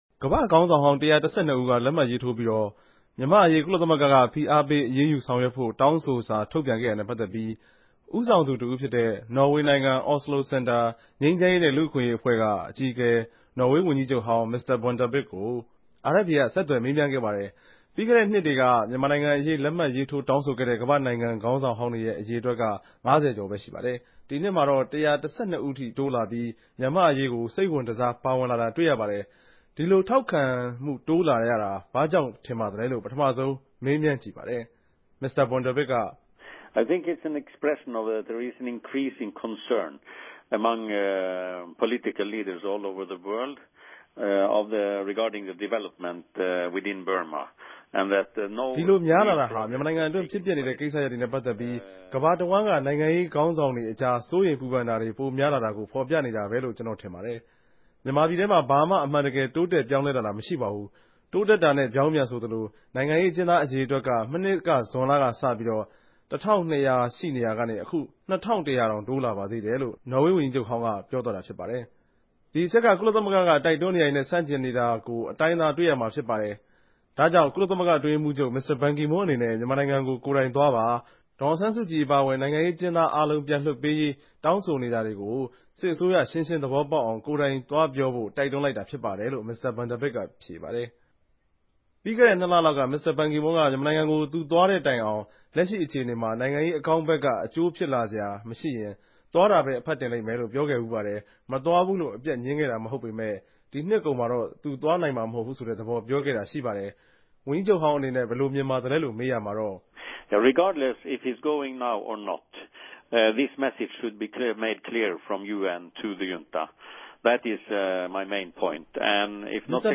မင်္စတာ ဗြန်ဒၝဗစိံြင့် ဆက်သြယ်မေးူမန်းခဵက်။